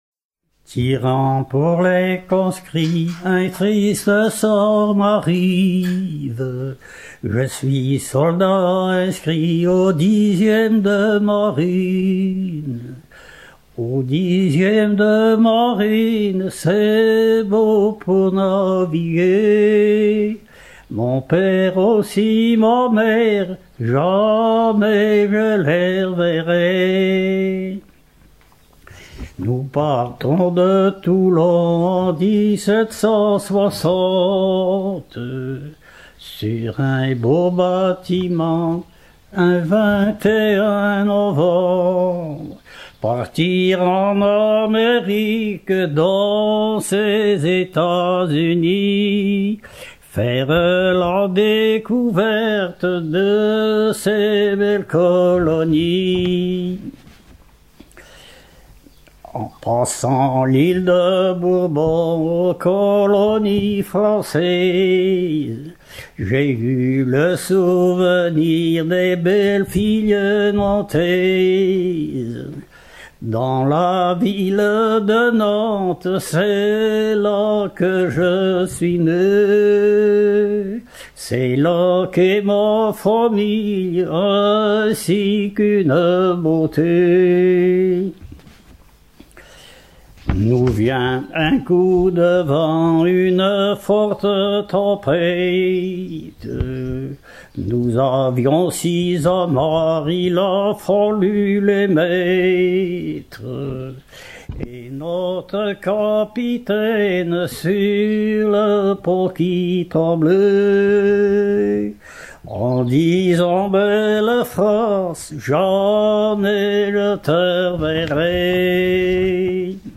Genre strophique
Chansons maritimes